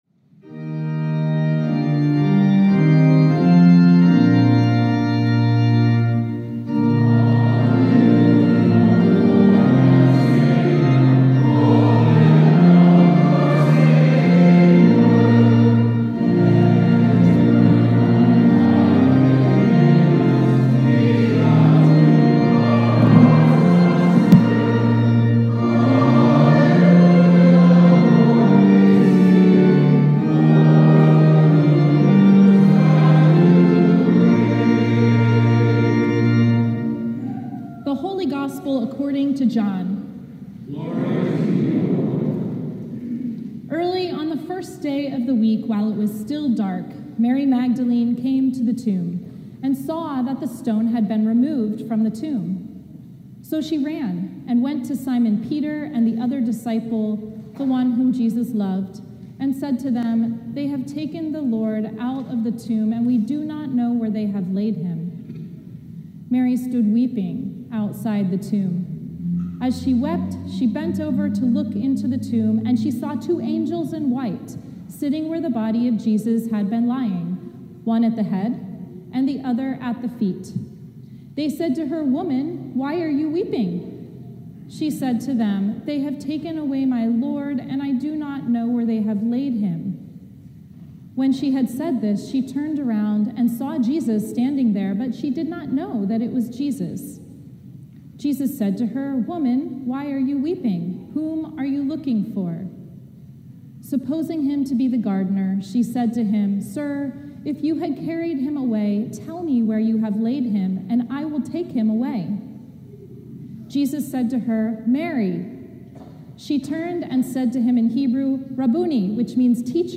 Sermon from the Ninth Sunday of Easter